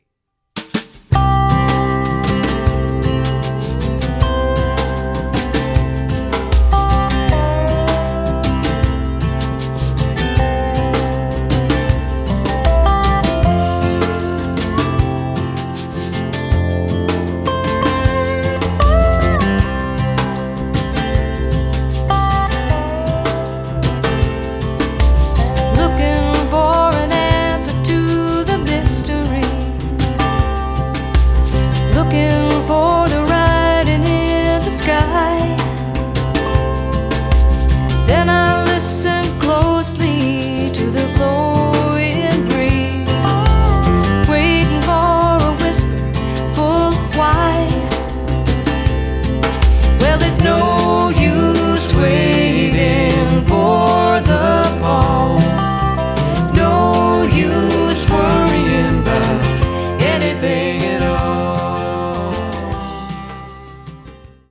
Americana, pop, country and bluegrass
drummer/percussionist
slide guitarist
keyboardist
electric guitarist